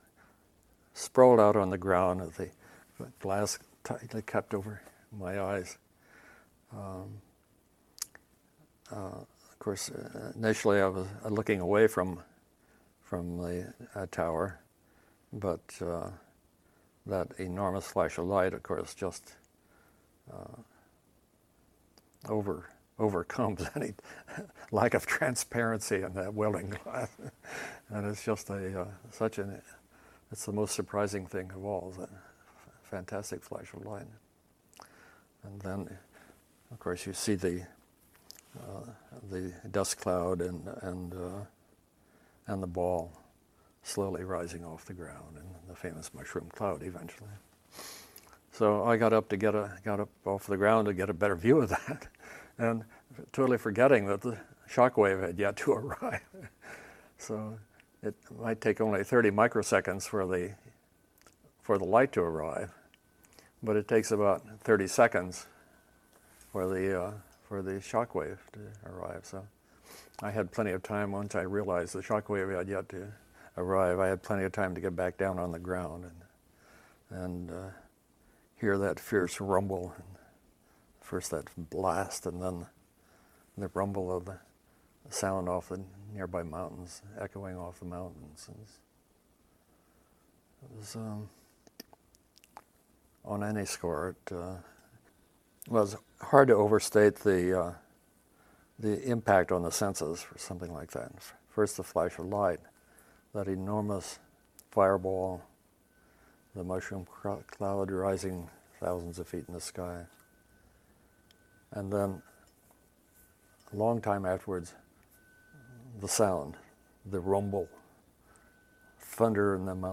In our search for understanding the role of sound in life with the bomb, we must turn to another form of aural record - that of eyewitness accounts. Here are a few examples, excerpted from longer oral histories: